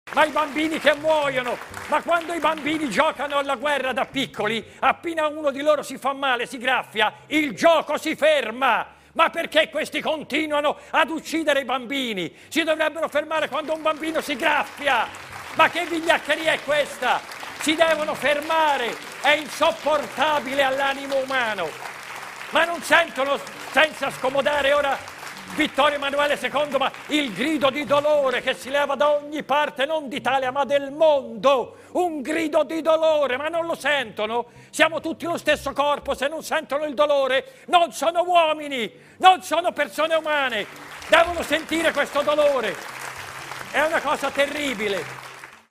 actor y director Mp3 Sound Effect Roberto Benigni, actor y director italiano, alzó la voz por los niños de Palestina y el gen0c1dio en Gaza, durante el programa de televisión Propaganda Live del canal italiano La 7.